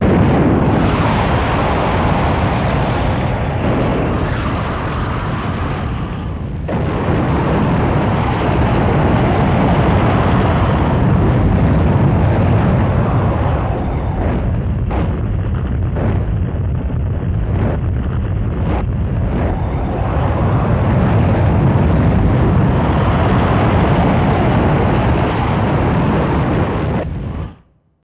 Geyser Long Sustain No Loop, Mono